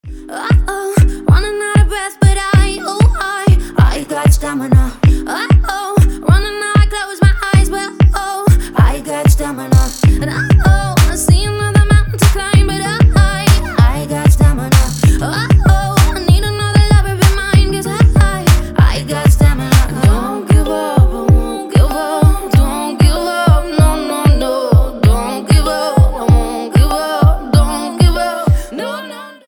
• Качество: 256, Stereo
поп
женский вокал
мелодичные
dance
красивый женский голос